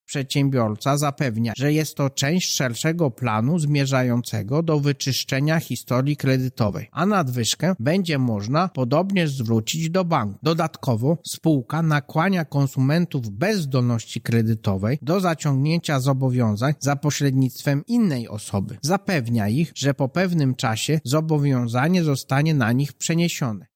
O innych praktykach firmy mówi prezes UOKiK Marek Niechciał